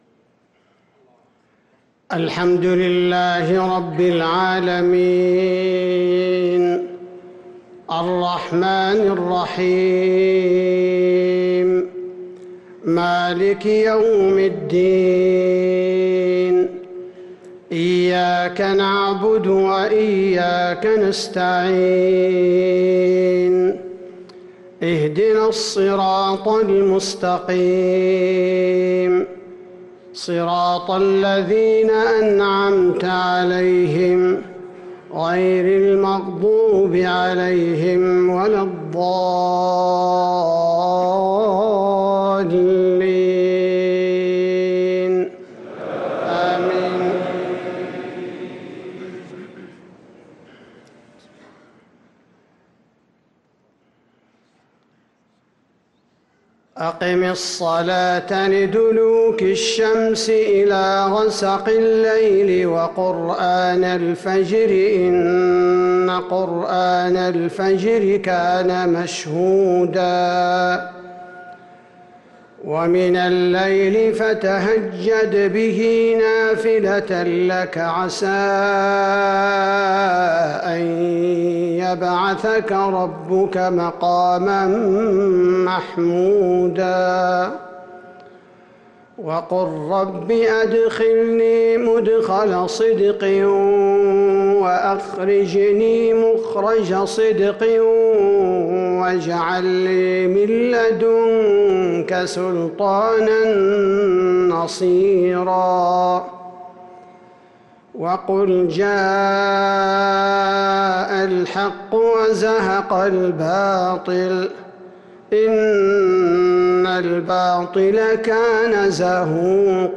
صلاة المغرب للقارئ عبدالباري الثبيتي 13 ذو الحجة 1444 هـ